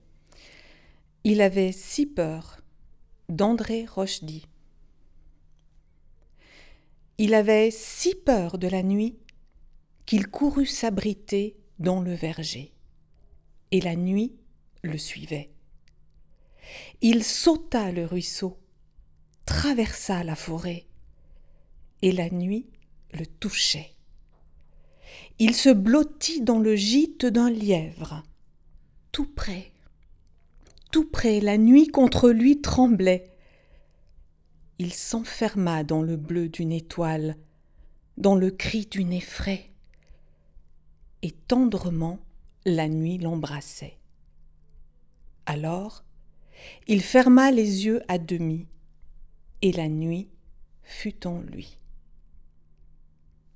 Fichier audio du poème utilisé dans le parcours Le poème mis en voix FRA 3-4